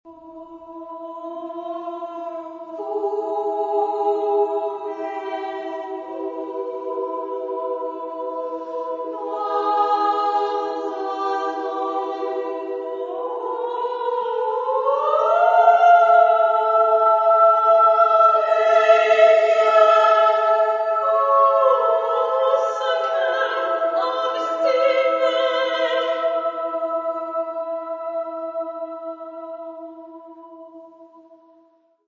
Género/Estilo/Forma: Profano ; Poema ; contemporáneo
Carácter de la pieza : lento ; misterioso
Tipo de formación coral: SMA  (3 voces Coro femenino )
Tonalidad : aleatoria